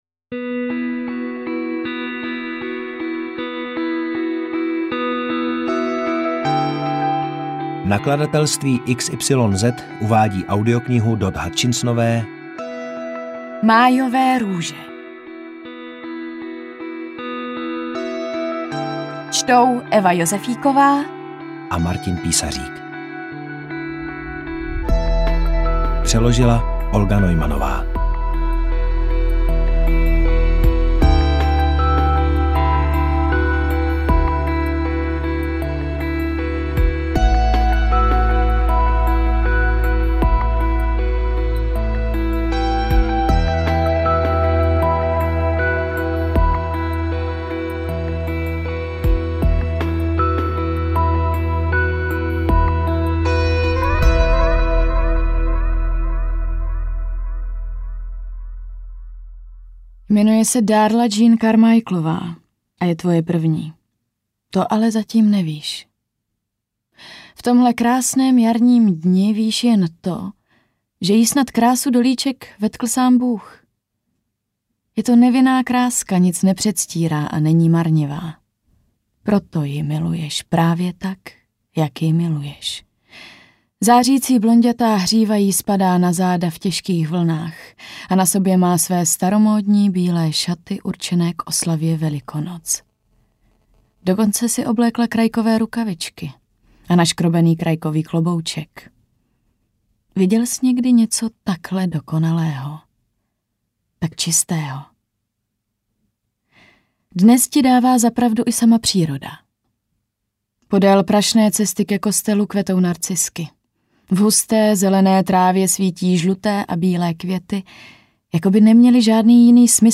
AudioKniha ke stažení, 45 x mp3, délka 10 hod. 34 min., velikost 576,8 MB, česky